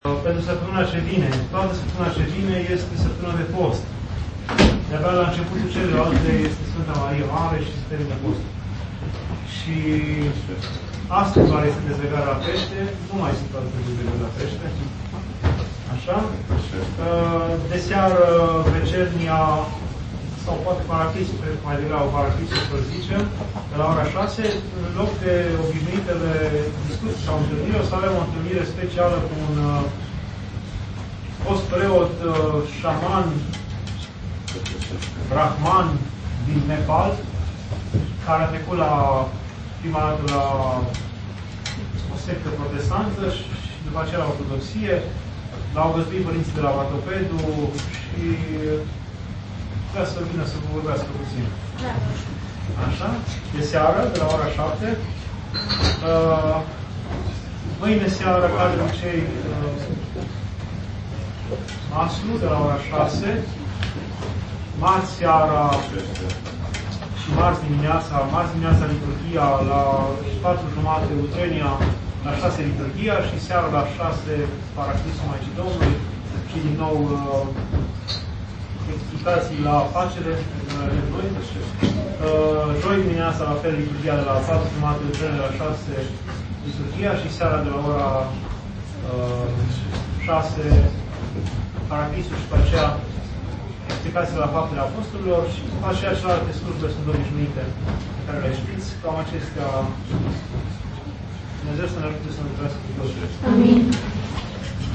Anunțuri